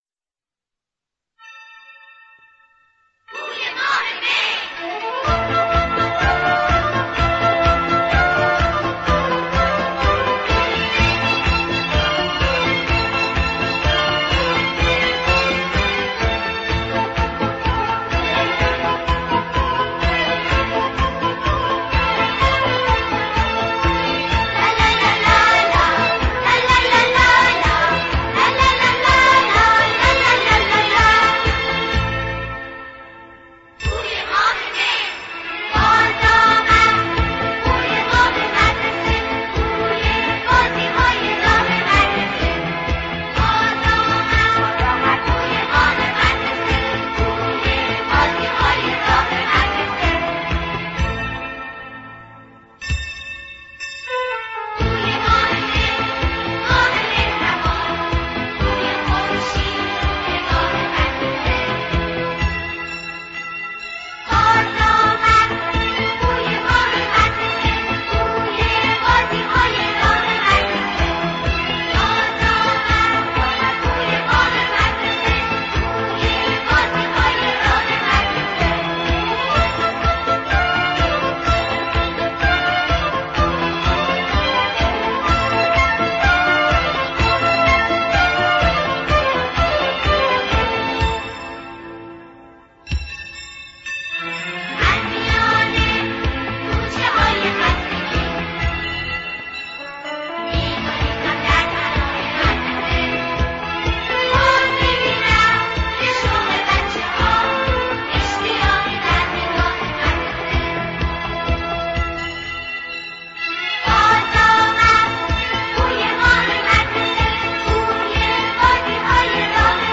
ترسناک ترین آهنگ جهان.mp3
ترسناک-ترین-آهنگ-جهان.mp3